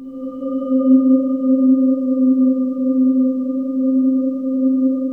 Index of /90_sSampleCDs/USB Soundscan vol.28 - Choir Acoustic & Synth [AKAI] 1CD/Partition D/05-SPECTRE